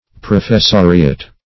Professoriat \Pro`fes*so"ri*at\, n.
professoriat.mp3